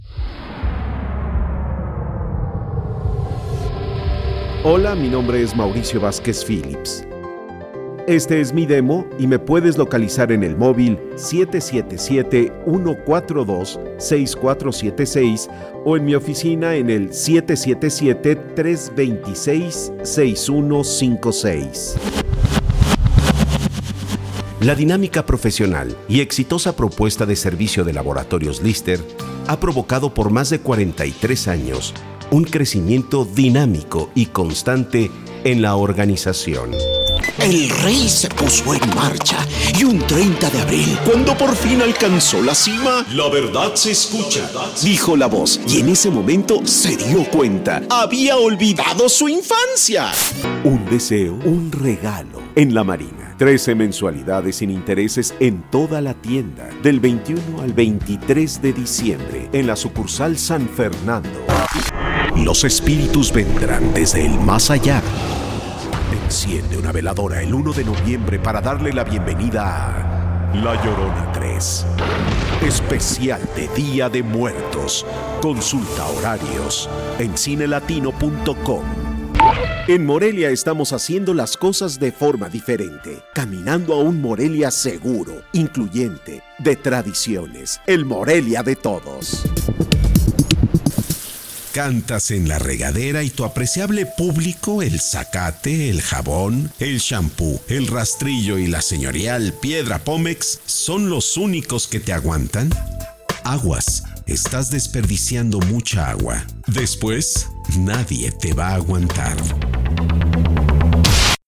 西班牙语男声
低沉|激情激昂|大气浑厚磁性|沉稳|娓娓道来|科技感|积极向上|时尚活力|神秘性感|调性走心|感人煽情|素人